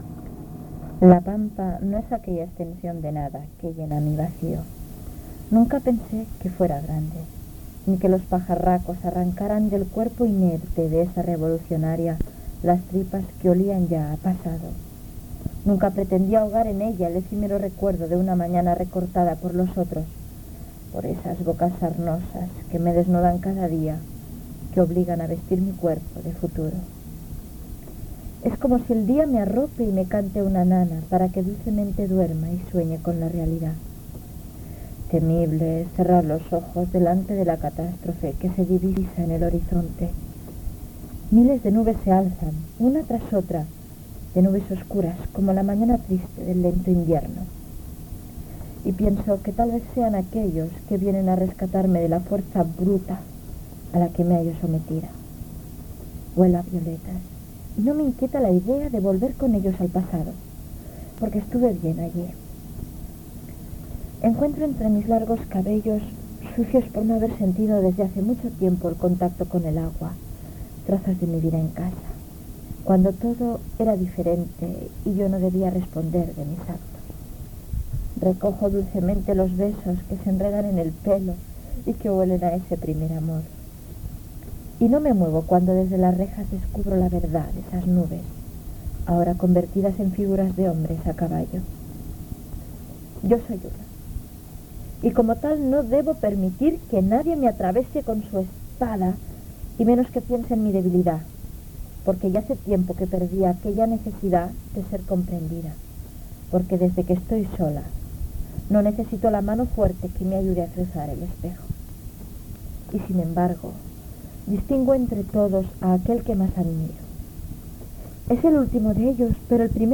Lectura d'una narració, diversos temes musicals amb les seves presentacions, identificació de l'emissora, data en la qual s'està fent el programa, identificació, tema musical, narració sonora